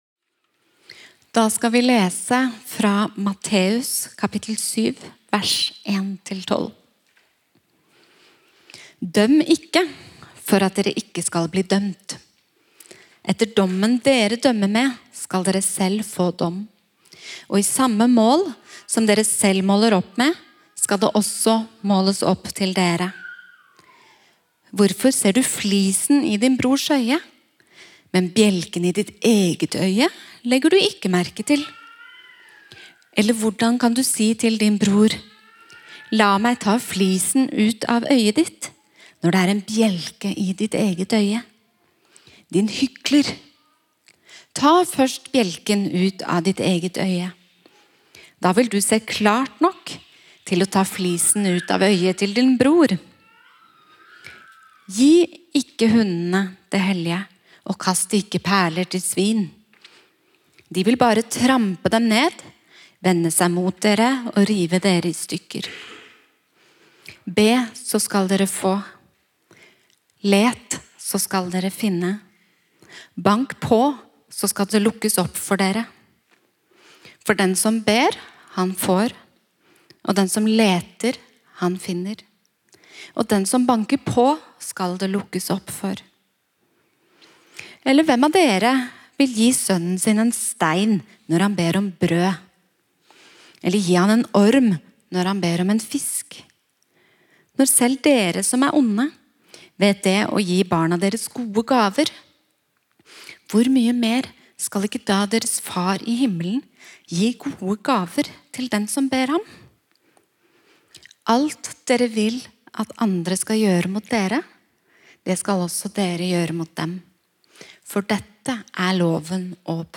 Forkynnelse og opptak fra Oslo misjonskirke Betlehem